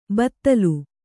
♪ battalu